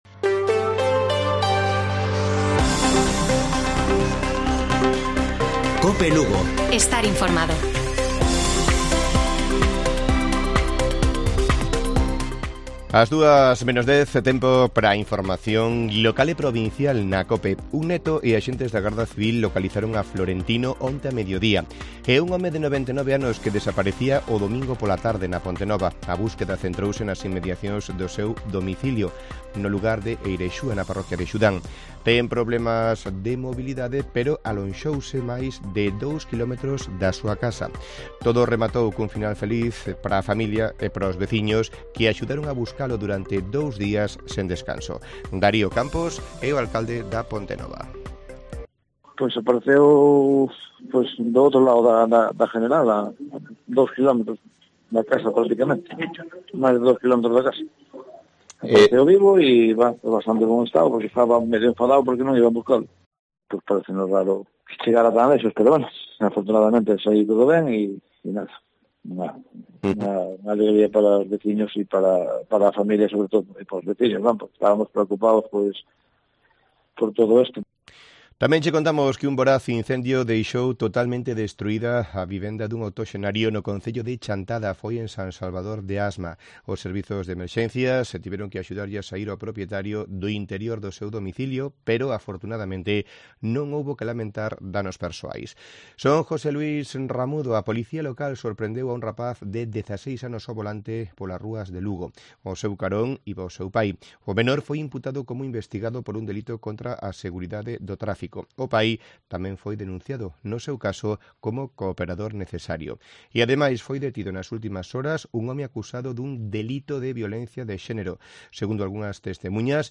Informativo Mediodía de Cope Lugo. 3 de mayo. 13:50 horas